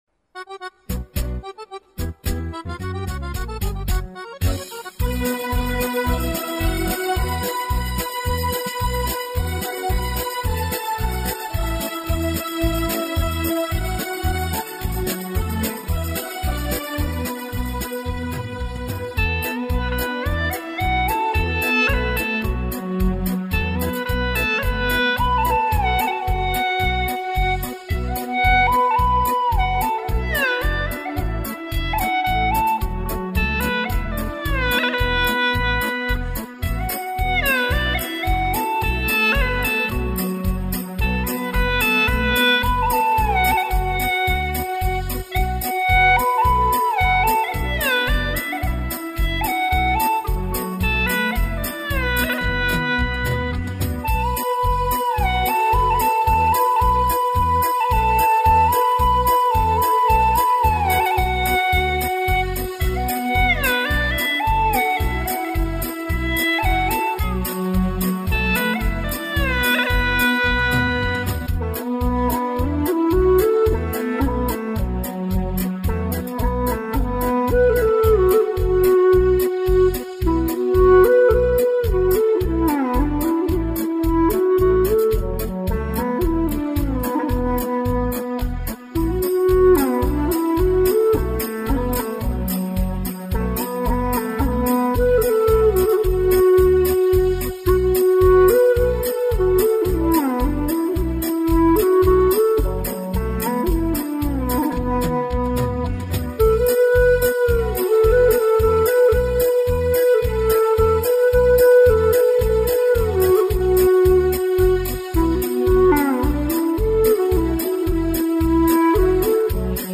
调式 : D